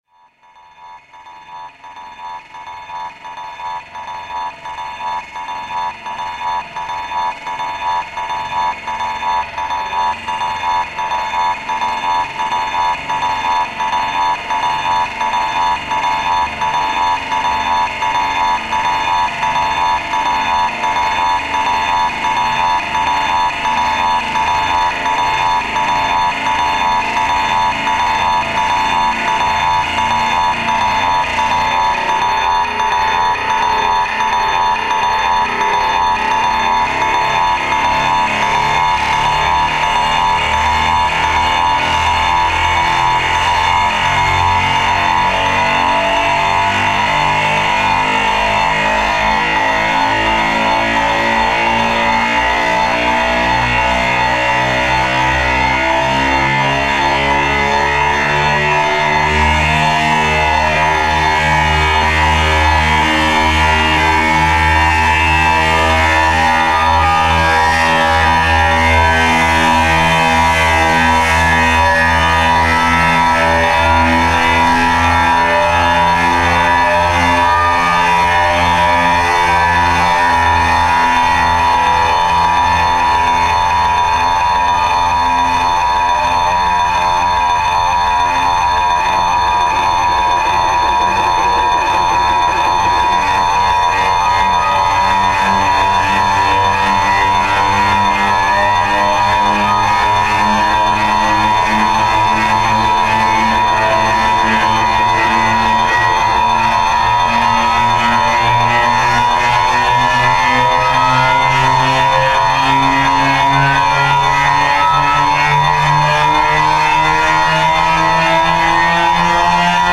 - Pièce en bois et capitonnée dim.3x3x3m, stroboscopes, pièce sonore de 30min en boucle.